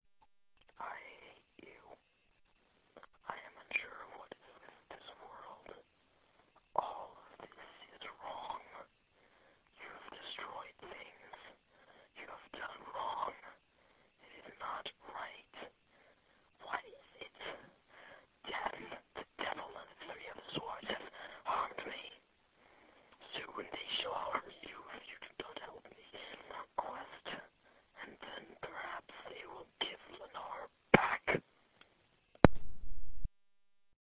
I was just conversing about this with another player, and we both think that the voice on the audio for this proof actually sounds like me trying to whisper into a phone or other digital mic.